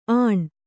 ♪ āṇ